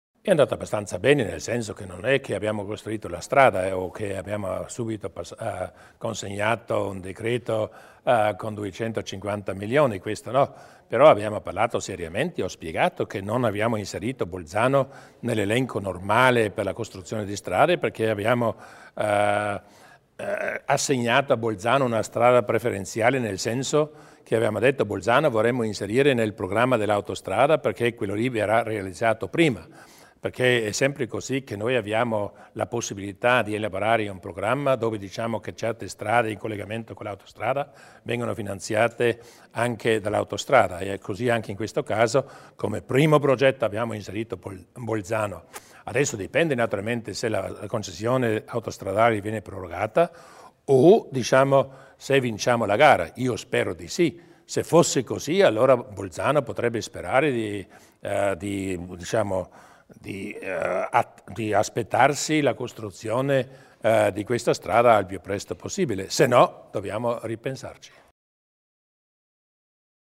Il Presidente Durnwalder spiega i progetti per la città di Bolzano